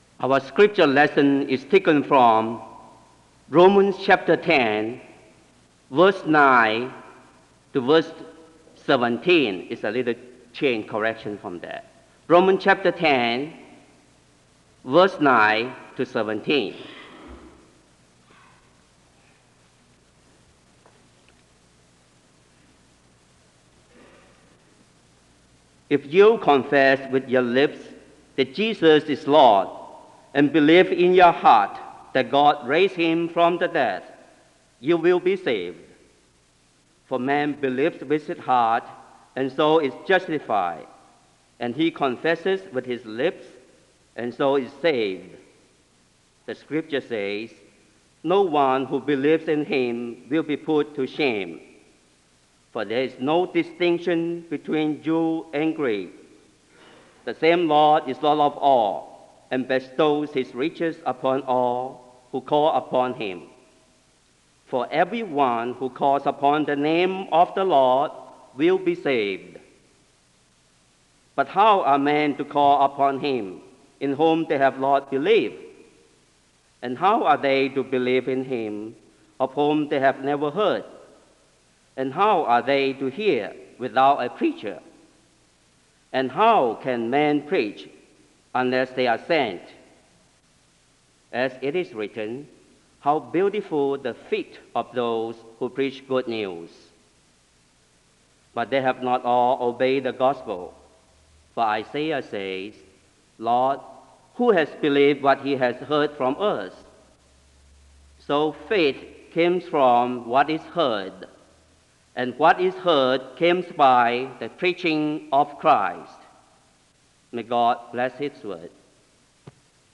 Chapel begins with a reading of Romans 10:9-17 (0:00-2:00). A prayer is given (2:00-2:50). Time of singing “This is My Story” (2:50-5:57).